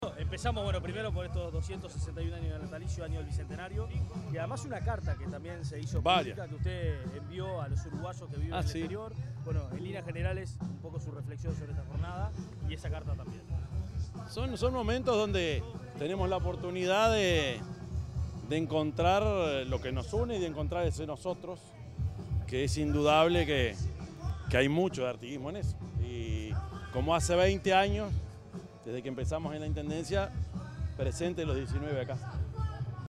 Declaraciones del presidente, Yamandú Orsi
El presidente de la República, profesor Yamandú Orsi, dialogó con la prensa luego de encabezar el acto conmemorativo de un nuevo aniversario del